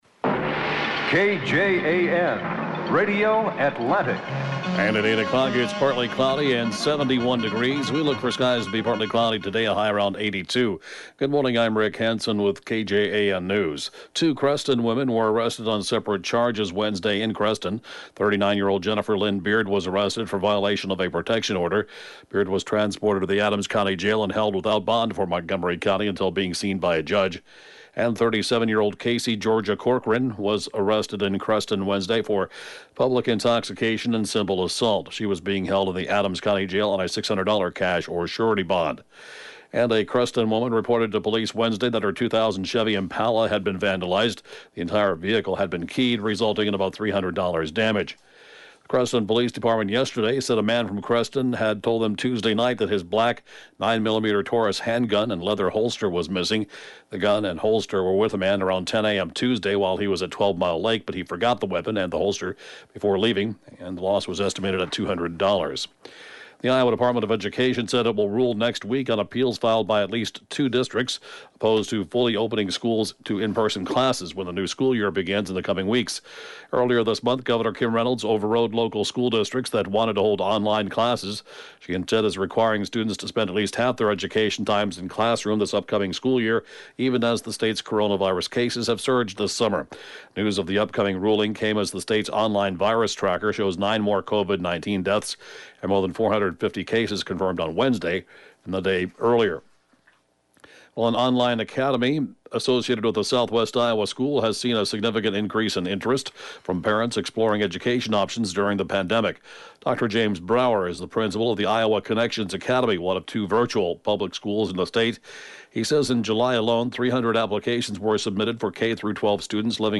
(Podcast) KJAN 8-a.m. News, 7/30/20